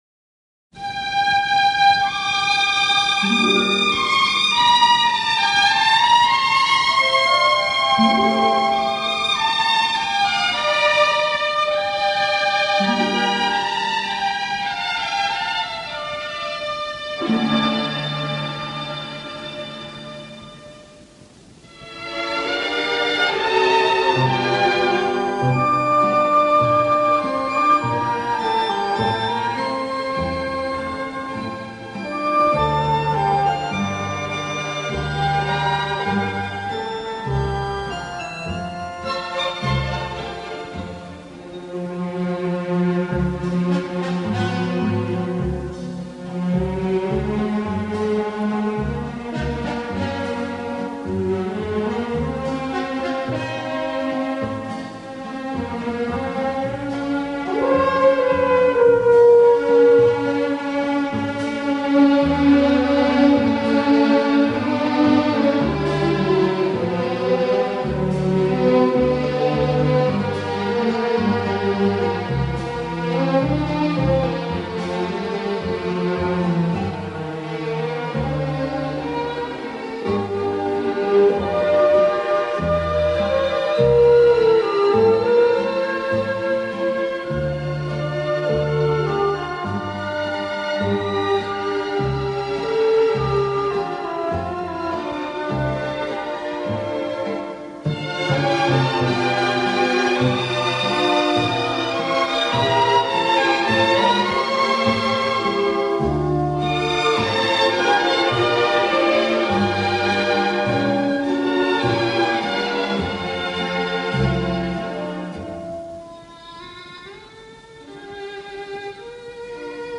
轻快、节奏鲜明突出，曲目以西方流行音乐为主。